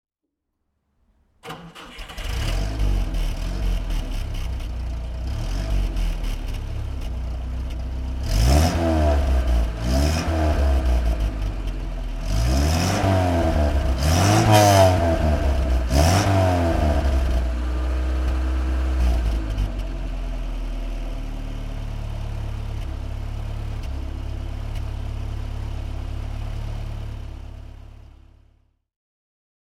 Borgward Isabella Coupé (1960) - Starten und Leerlauf